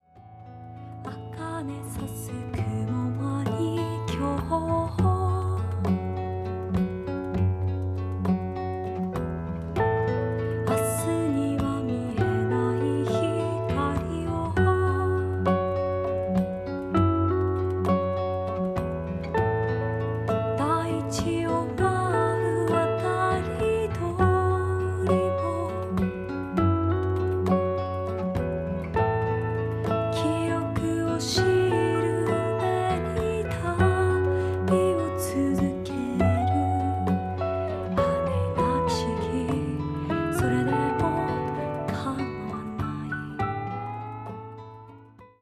深みのあるギター・サウンド。囁くような歌声に呼び起こされるのはいつかの思い出。